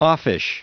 Prononciation du mot offish en anglais (fichier audio)
Prononciation du mot : offish